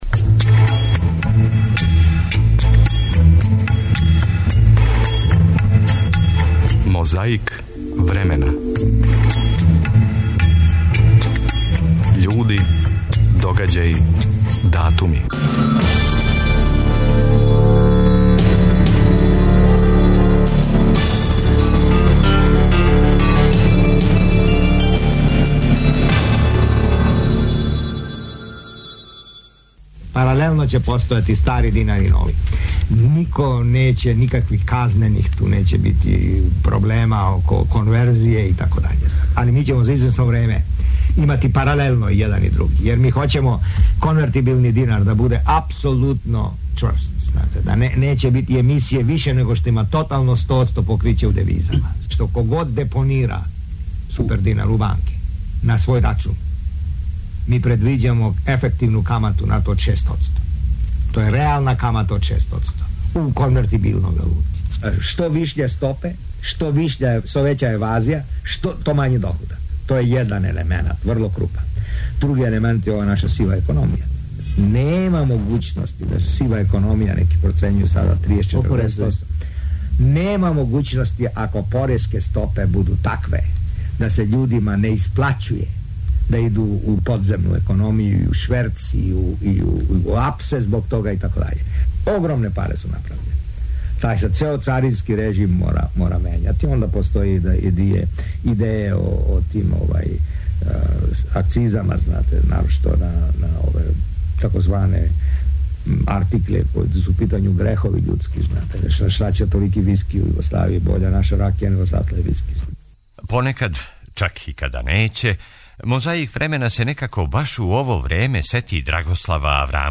Чућете шта је тог дана објавио Вечерњи дневник Првог програма Радио Београда.
Али, да бисмо и ми разумели све је прекрио преводилац.
Подсећа на прошлост (културну, историјску, политичку, спортску и сваку другу) уз помоћ материјала из Тонског архива, Документације и библиотеке Радио Београда.